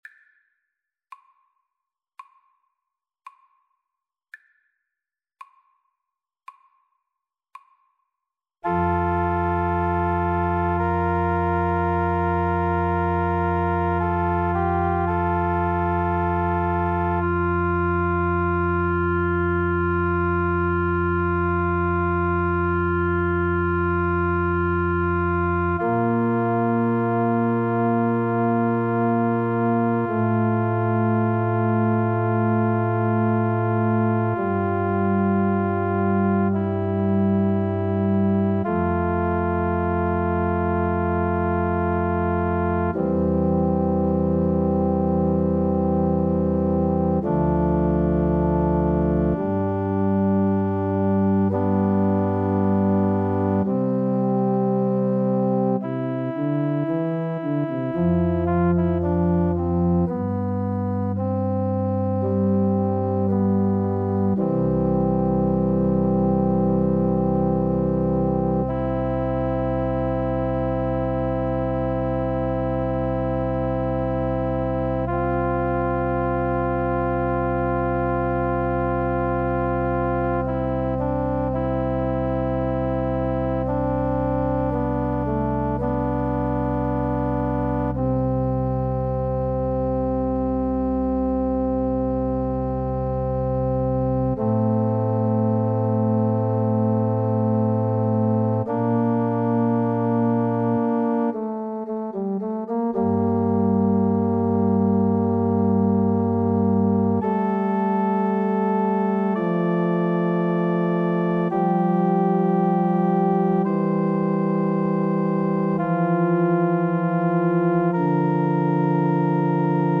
=56 Adagio
Classical (View more Classical French Horn Music)